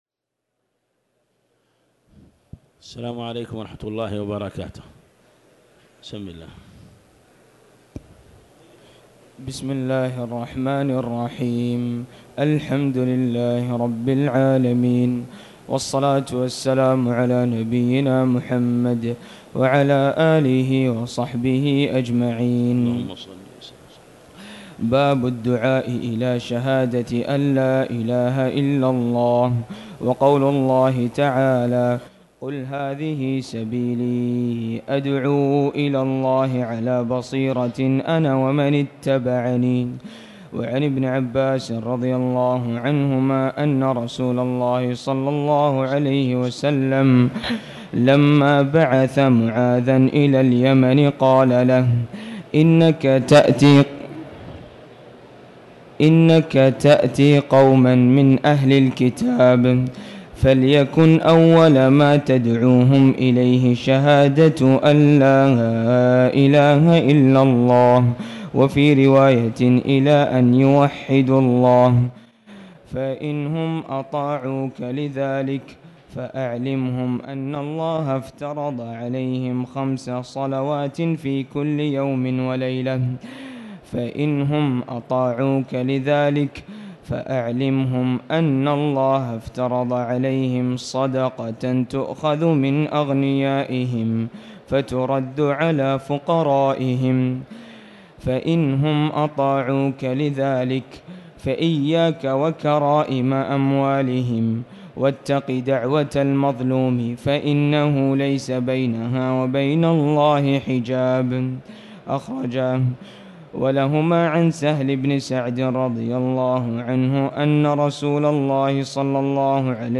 تاريخ النشر ٦ رمضان ١٤٤٠ هـ المكان: المسجد الحرام الشيخ